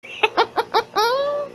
risadinha_2_7S1A9ri.mp3